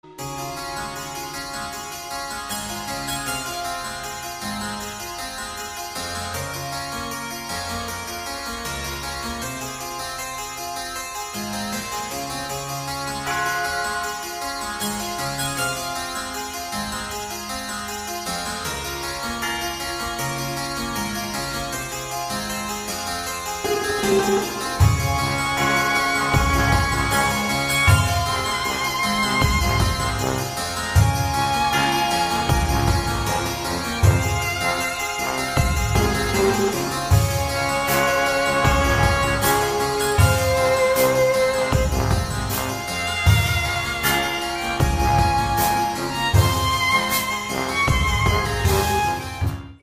Classic Darkwave